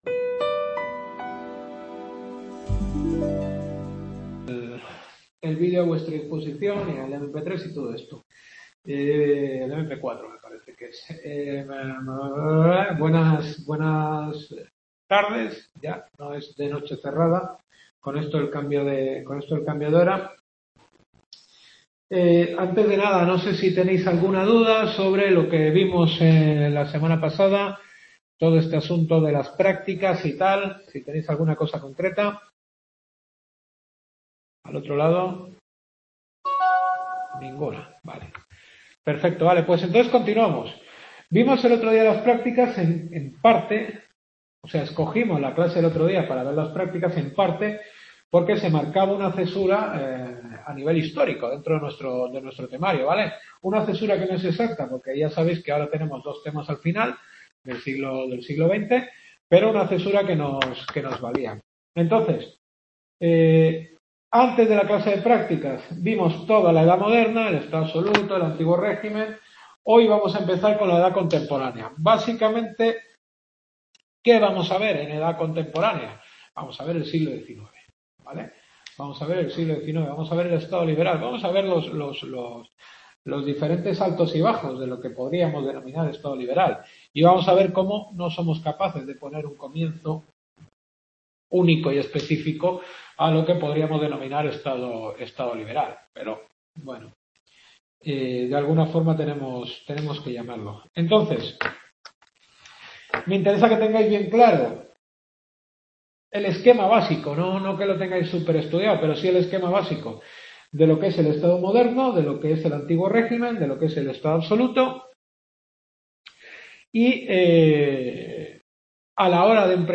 Séptima clase.